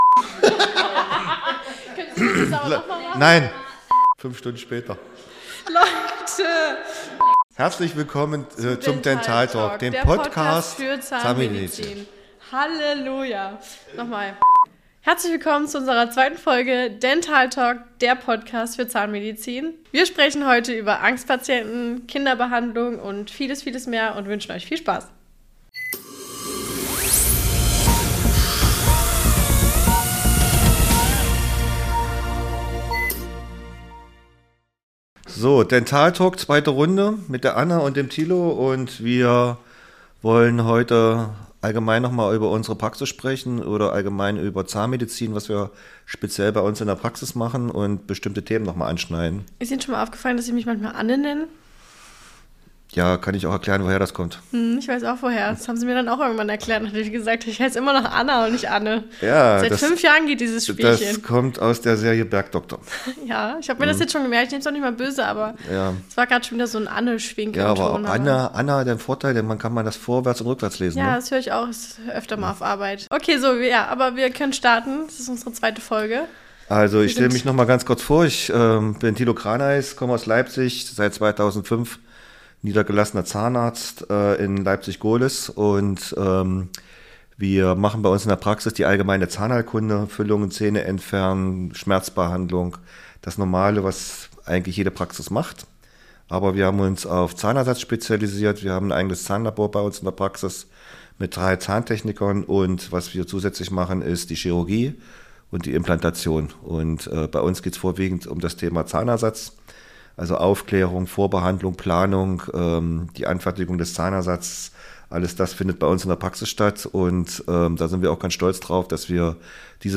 Sie erzählen, wie man schon bei den Kleinsten Vertrauen aufbaut, was Eltern beachten sollten und warum eine frühzeitige Zahnpflege so wichtig ist. Freu dich auf einen entspannten, informativen Austausch, der sowohl Fachpublikum als auch interessierte Laien anspricht.